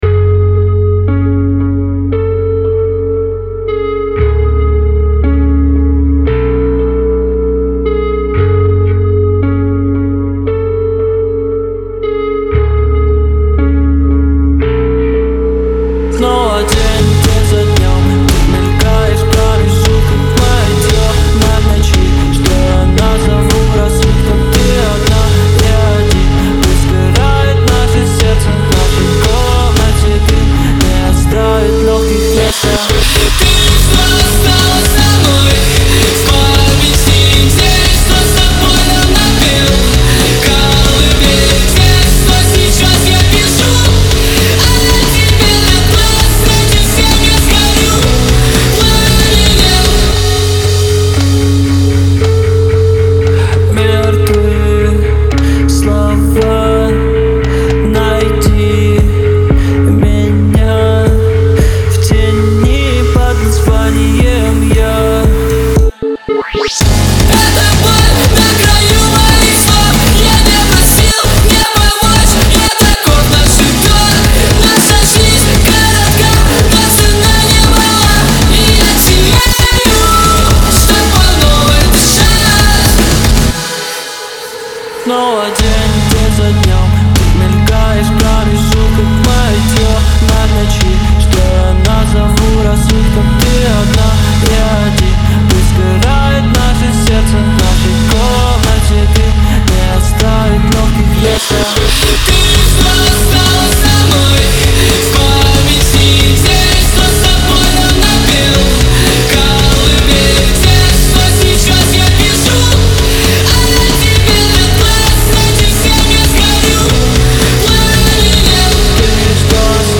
Трек размещён в разделе Русские песни / Рок.